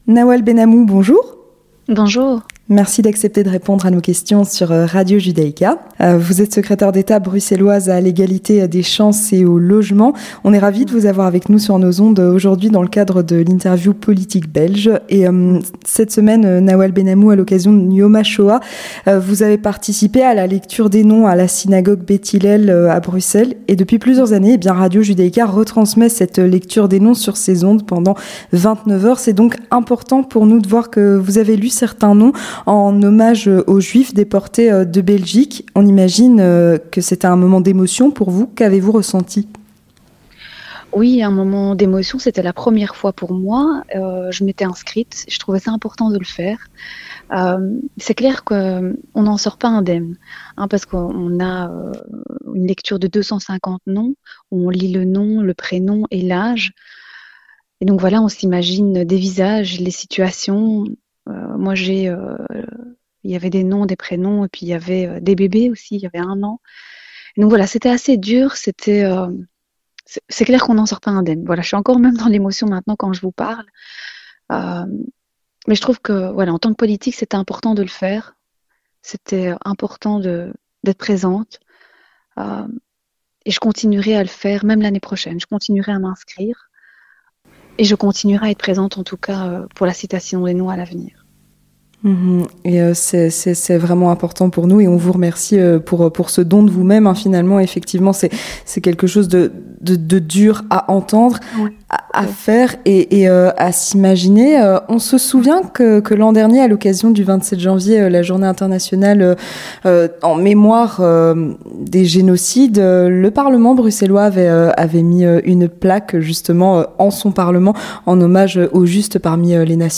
Vue de Belgique - L'interview politique belge
Avec Nawal Ben Hamou, secrétaire d'Etat bruxelloise à l'Egalité des chances